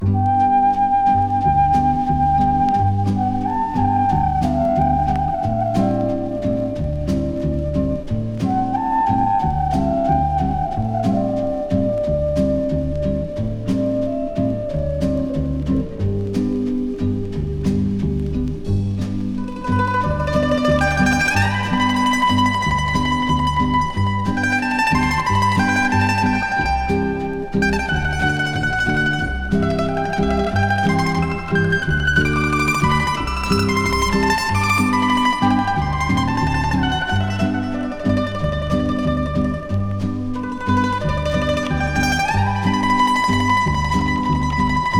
Stage & Screen, Soundtrack　USA　12inchレコード　33rpm　Stereo